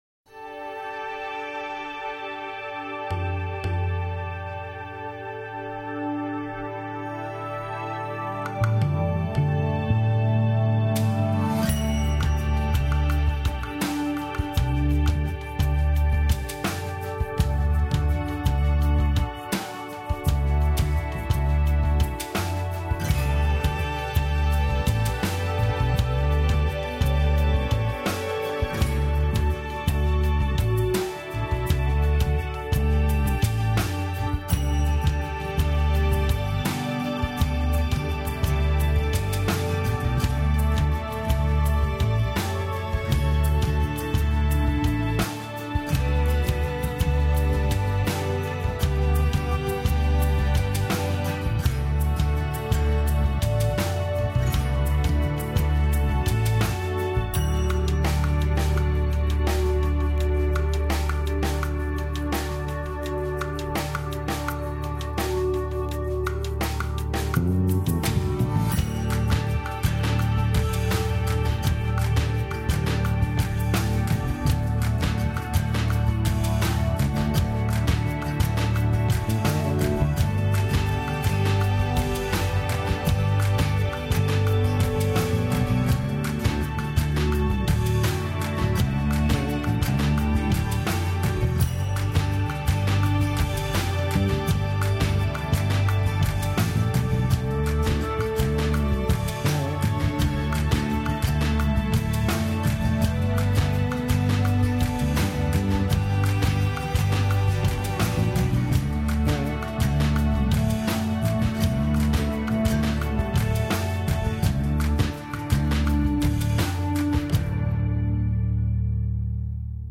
Q 呼び出し音を指定できますか？
A.呼び出し音は現在、変更できません。